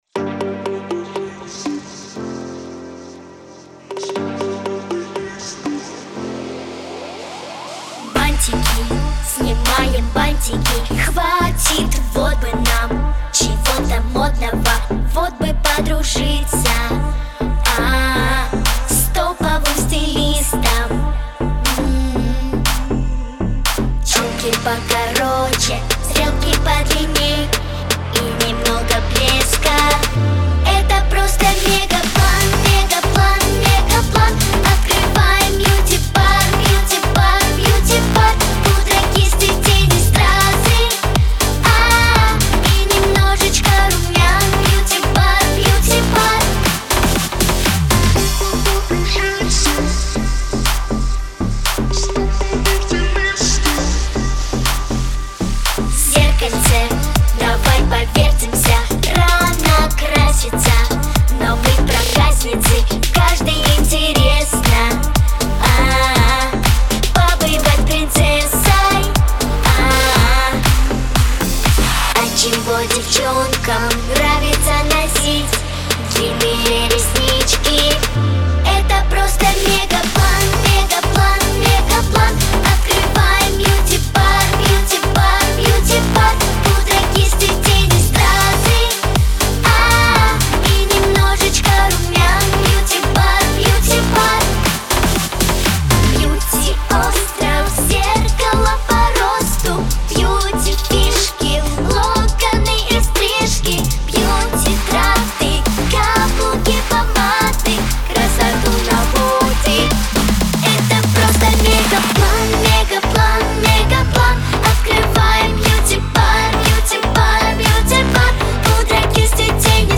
Характер песни: весёлый.
Темп песни: быстрый.